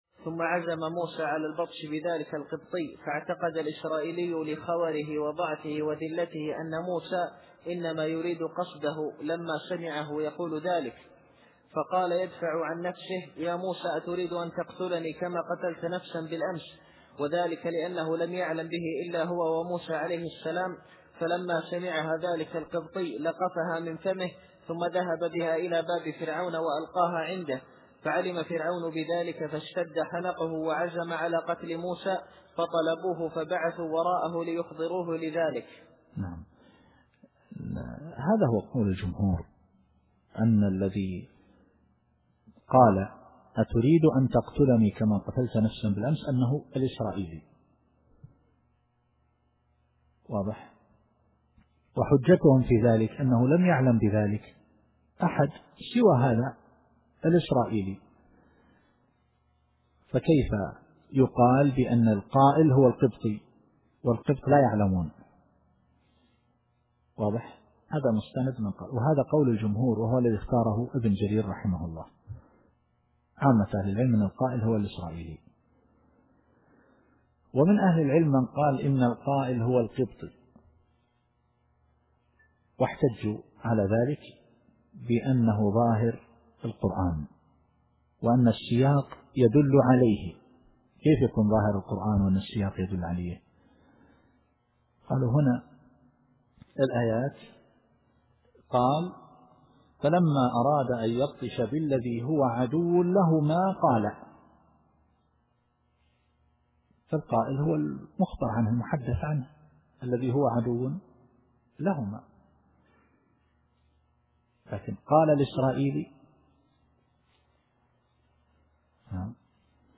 التفسير الصوتي [القصص / 19]